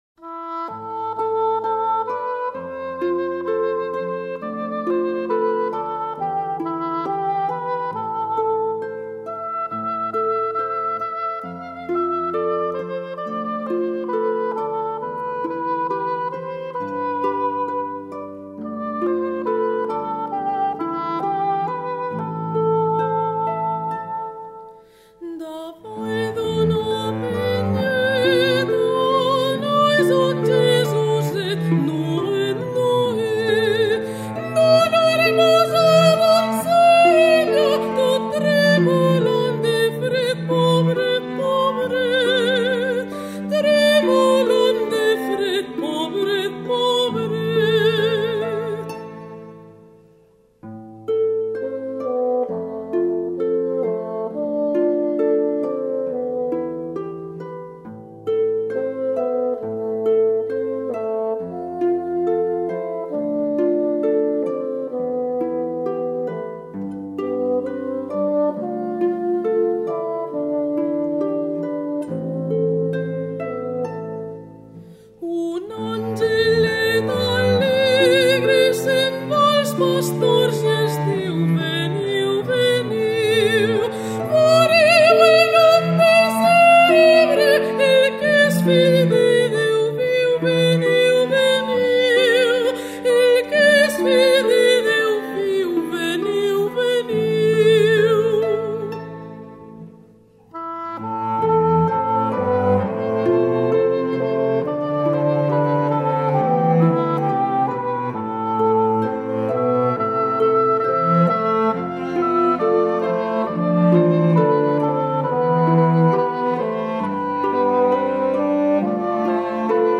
Grupo Instrumental, solistas y Coro